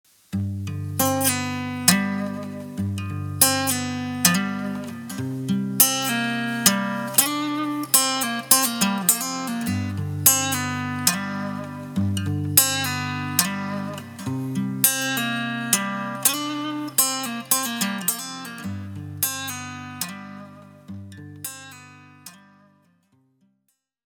Acoustic Guitar